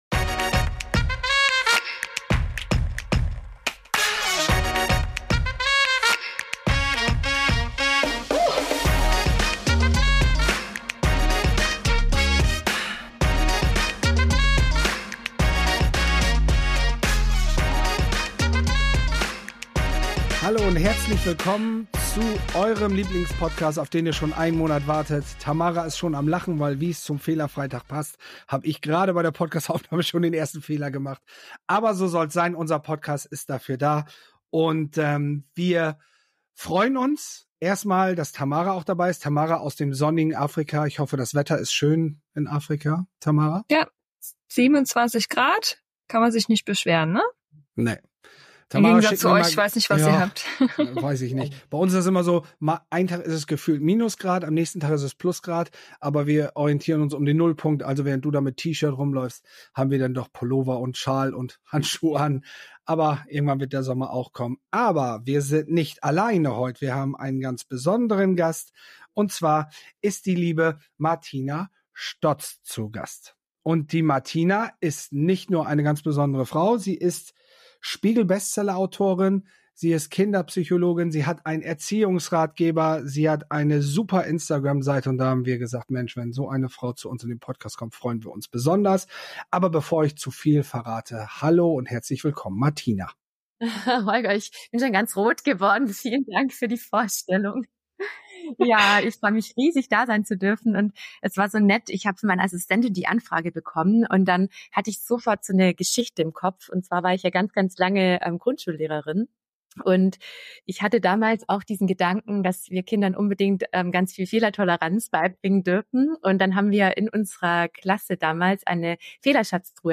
Fehlerfreudige Erziehung: Interview